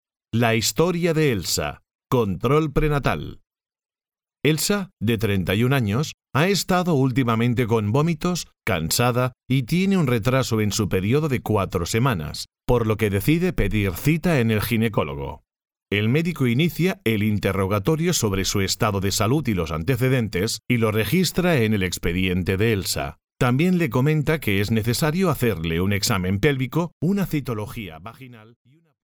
kastilisch
Sprechprobe: eLearning (Muttersprache):